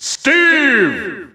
The announcer saying Steve's name in English and Japanese releases of Super Smash Bros. Ultimate.
Steve_English_Announcer_SSBU.wav